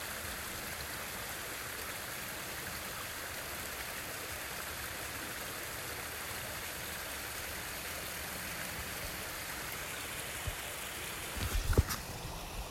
… und ihr hört erfrischende Bäche aus den Wäldern 😥😥😥!